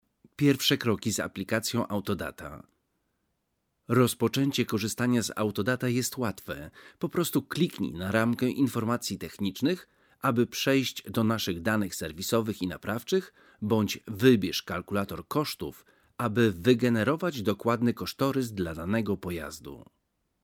Commercieel, Vriendelijk, Warm, Zacht, Zakelijk
E-learning